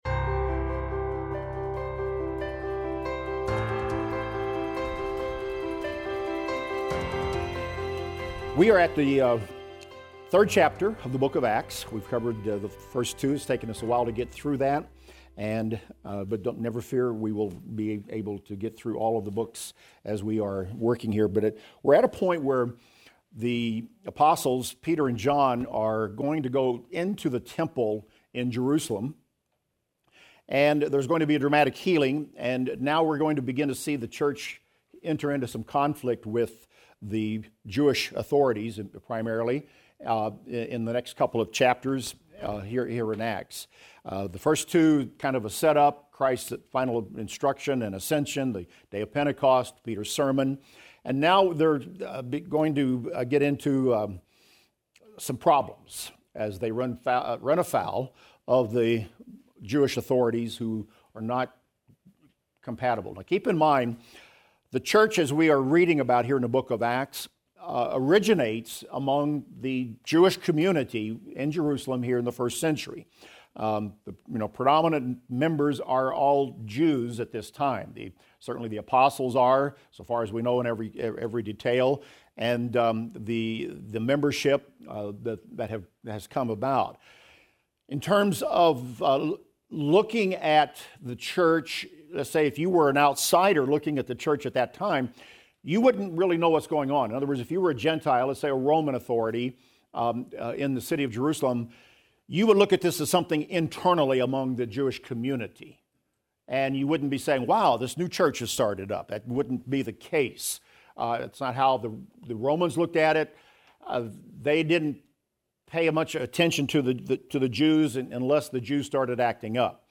In this class, we will discuss Acts 3:1-12 and the significance of Peter and John going to the temple to pray and the healing of a crippled beggar at the temple gate.